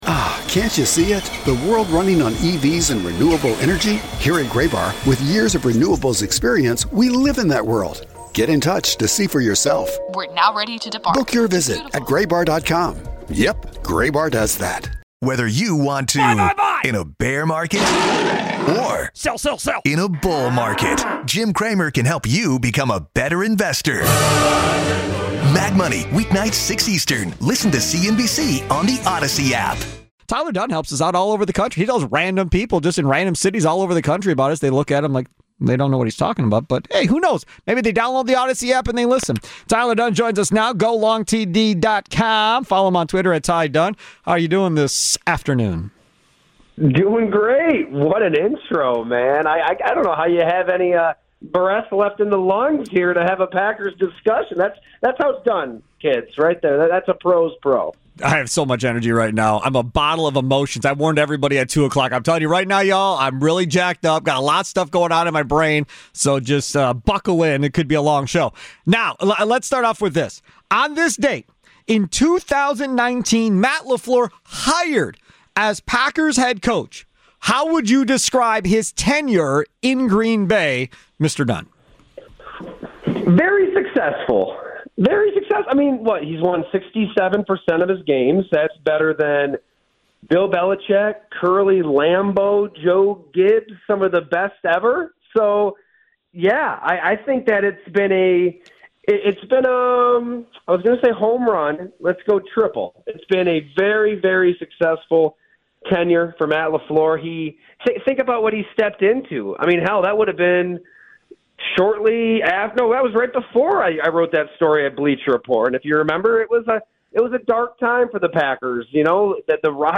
Also hear players coaches and our Football insiders.